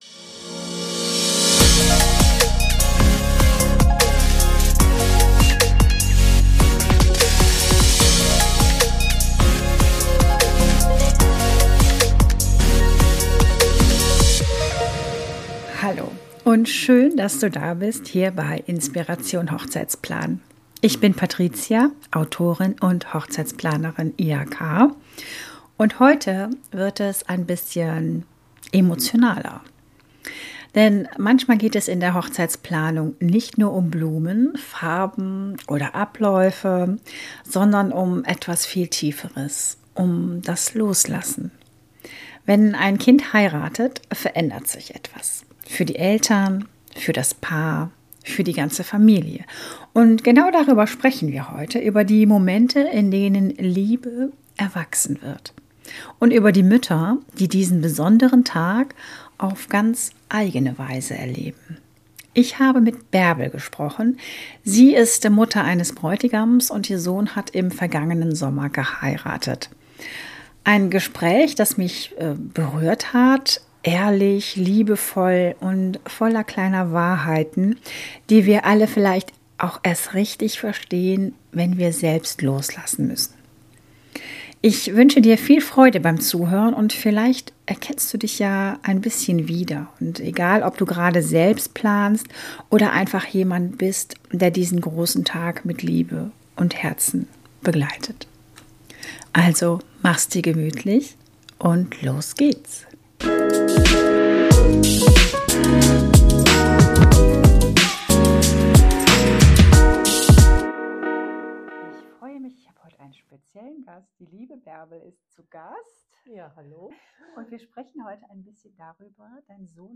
Ein Gespräch voller Gefühl, Wärme und kleiner Aha-Momente.